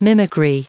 1692_mimicry.ogg